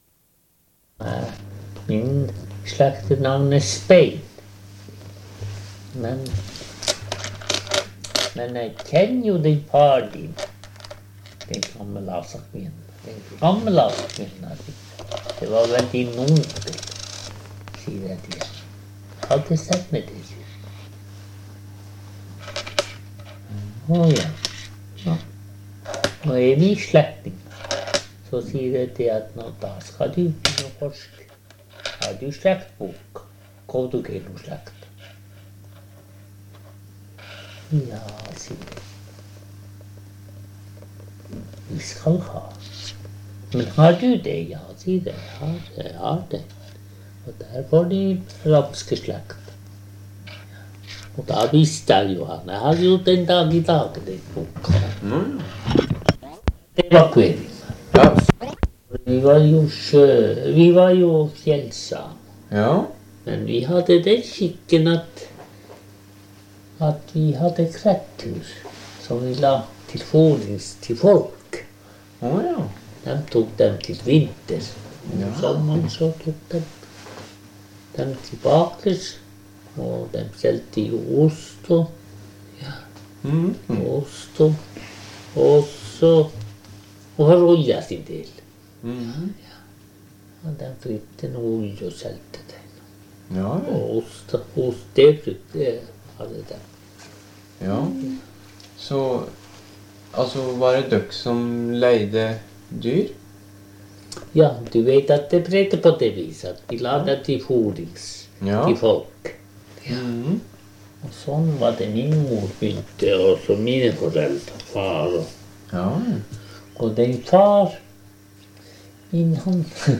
Sted: Kvalsund, Stallogargo